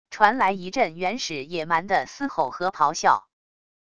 传来一阵原始野蛮的嘶吼和咆哮wav音频